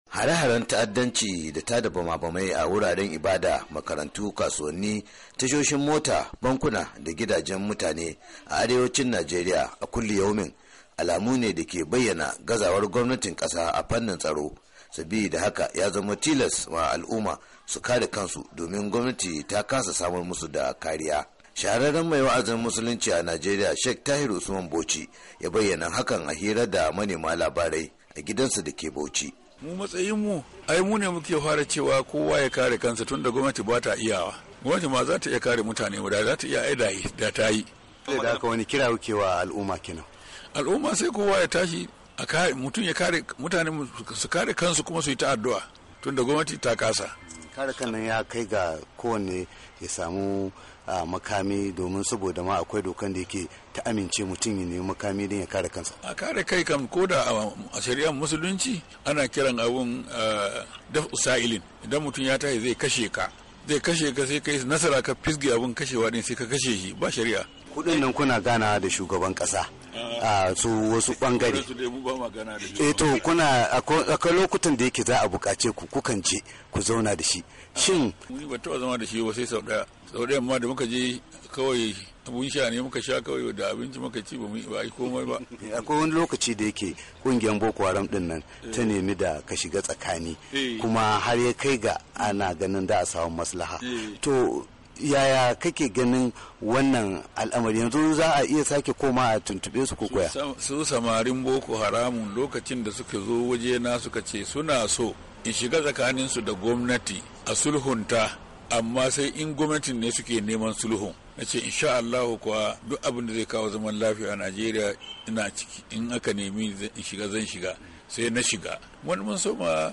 Shahararren mai wa’azin musulinci a Najeriya Sheikh Dahiru Usman Bauchi ya bayyana hakan awani taron manema labarai a gidansa dake Bauchi inda yace, “a matsayinmu ai mune ke fara cewa kowa yakare kansa tunda gwamnati bata iyawa,” yadai nuna cewa gwamnatin bazata iya kare mutane ba domin inda zata iya da ta riga tayi, ya kuma ce mutane su tabbatar sun kare kansu.